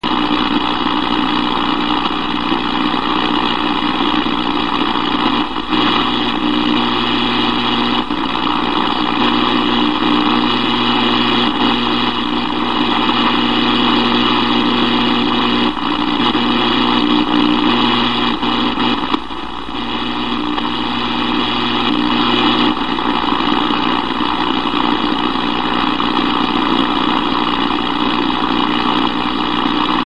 The files in this section are recordings of RF noise from electric utilities, BPL and industrial equipment.
Noise Source: Powerline Noise
Where Found: Recordings were made on 13,690 and 14,319 kHz. However, this kind of noise can occur in many places in the HF spectrum